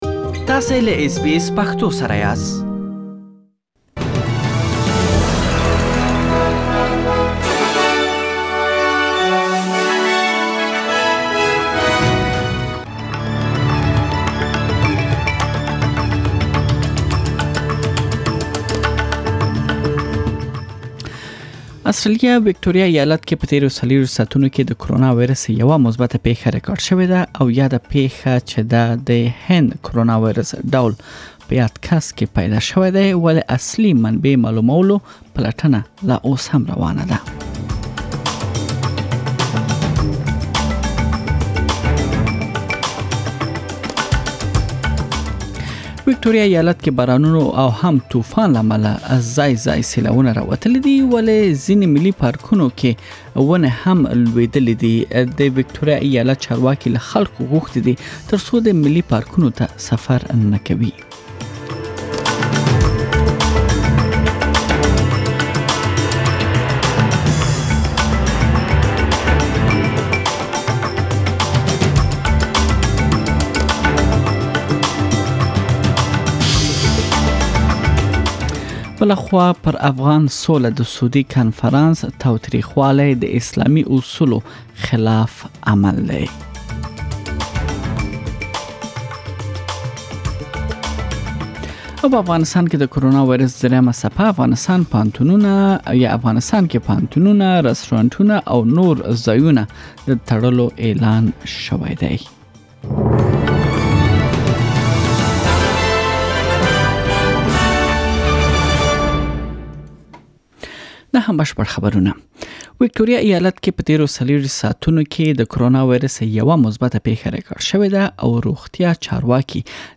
د نن ورځې مهم خبرونه